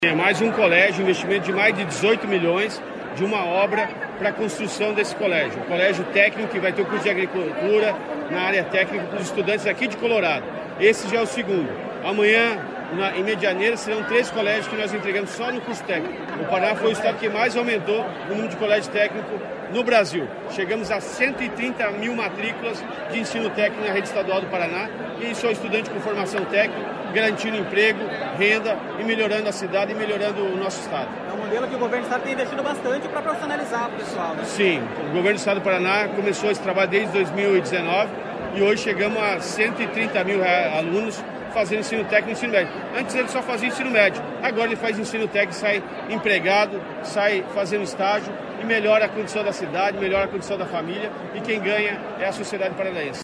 Sonora do secretário Estadual da Educação, Roni Miranda, sobre a inauguração do CEEPA de Colorado